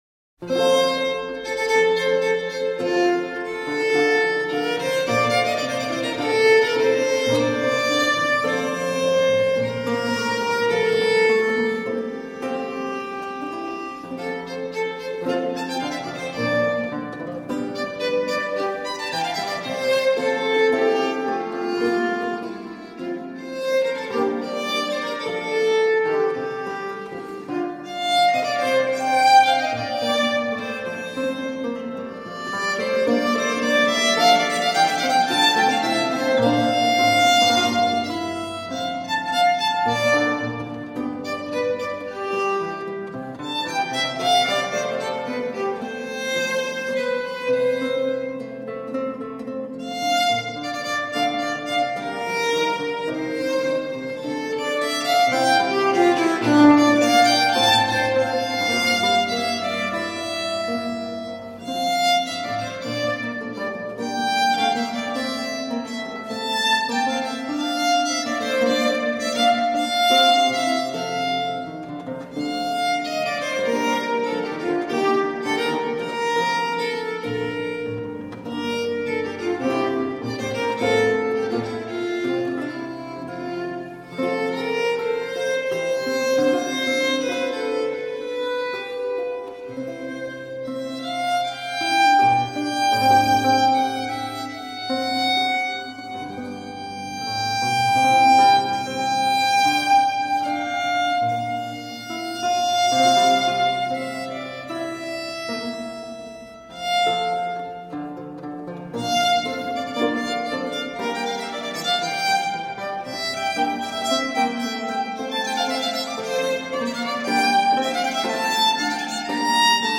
17th century baroque ensemble.
Classical, Orchestral, Baroque, Instrumental
Organ, Violin